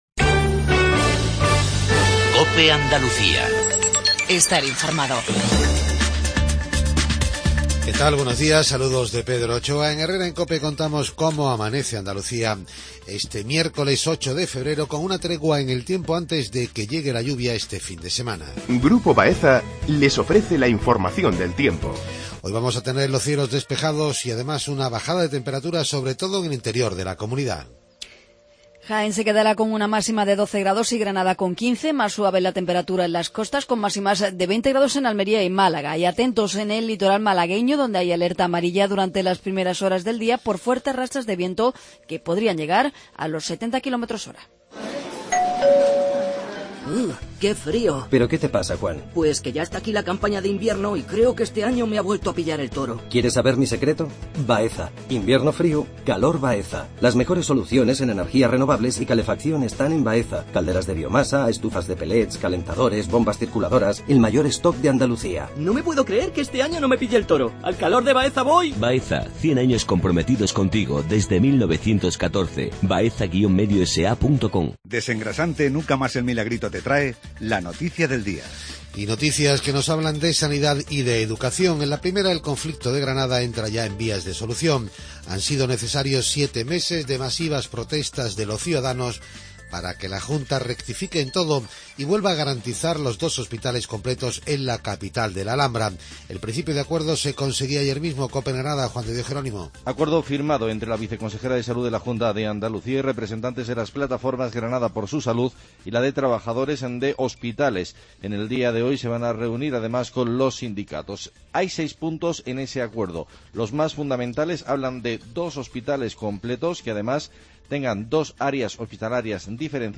INFORMATIVO REGIONAL/LOCAL MATINAL 7:50